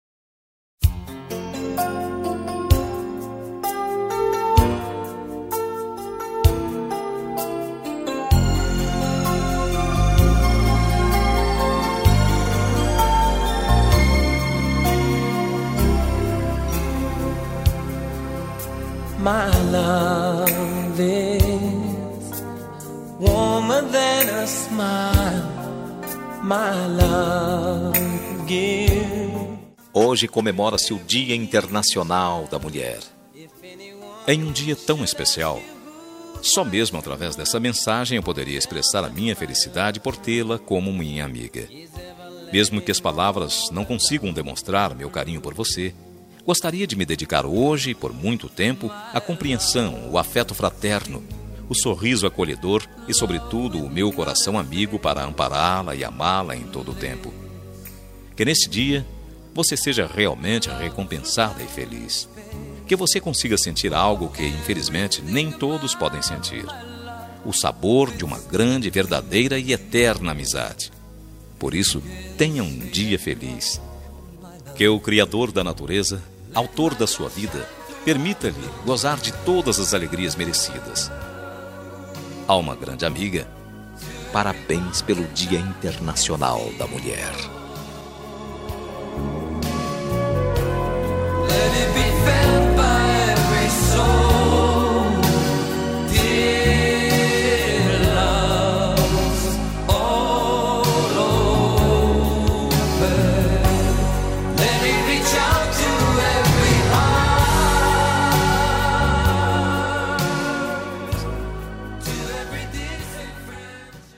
Dia das Mulheres Para Amiga – Voz Masculina – Cód: 5358